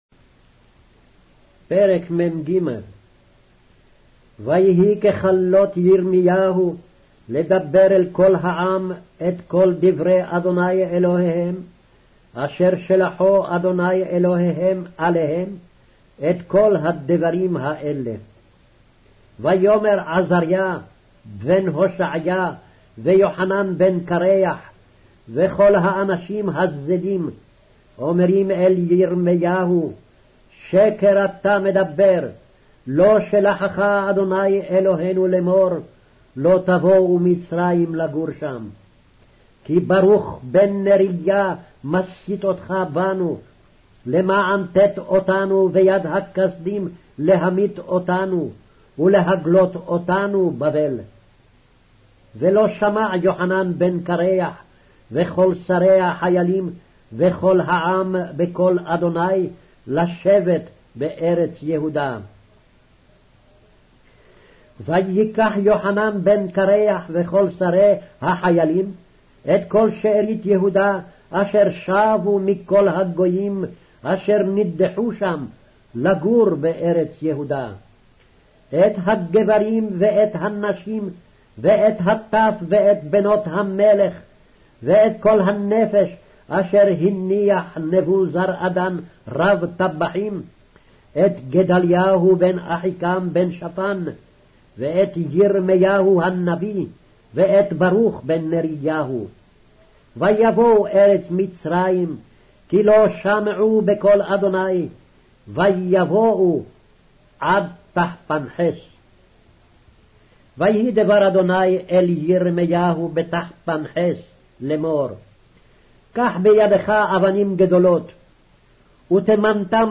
Hebrew Audio Bible - Jeremiah 38 in Urv bible version